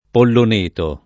[ pollon % to ]